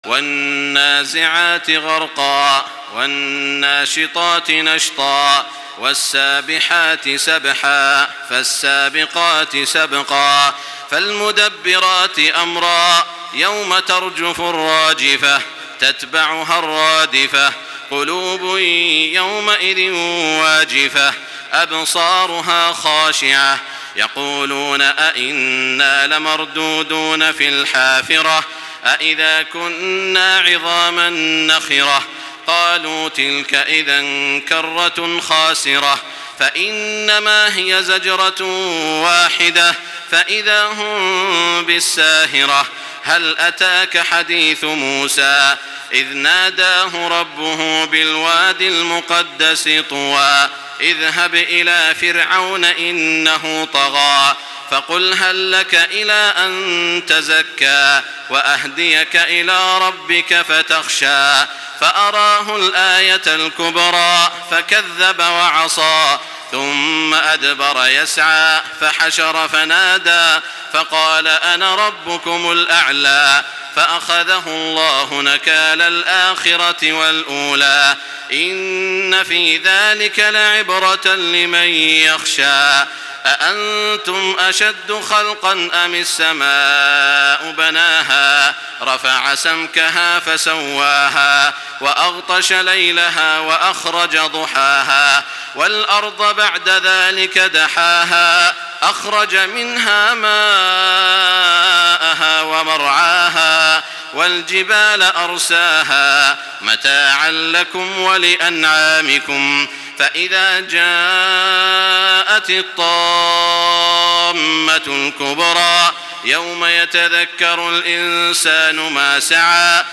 Surah An-Naziat MP3 in the Voice of Makkah Taraweeh 1425 in Hafs Narration
Listen and download the full recitation in MP3 format via direct and fast links in multiple qualities to your mobile phone.
Murattal